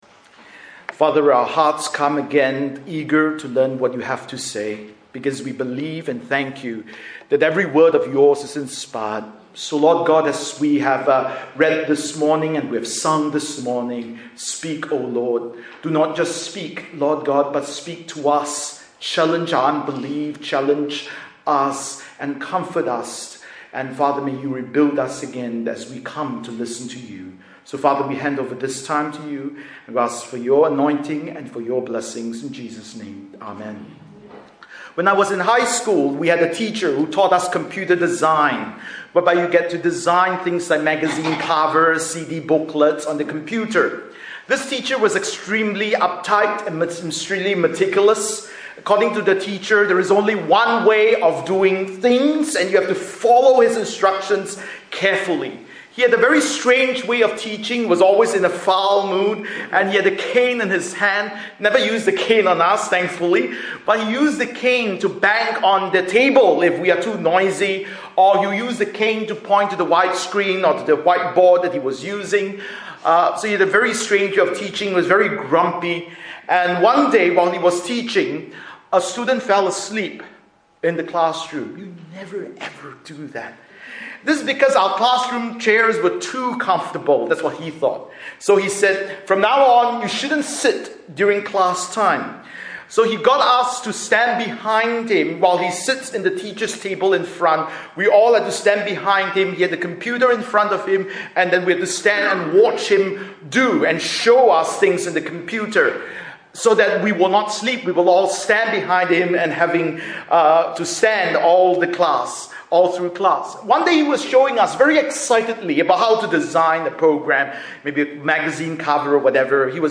Bible Text: 1 Samuel 2:11-21 | Preacher